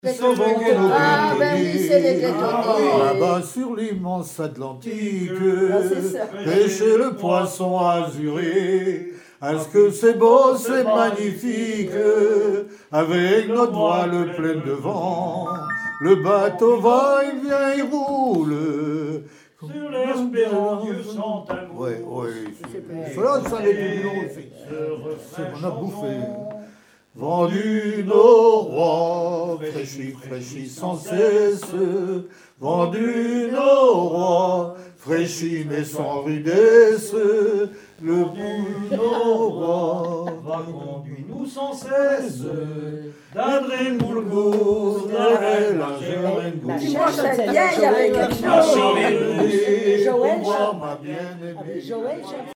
Enquête Douarnenez en chansons
Pièce musicale inédite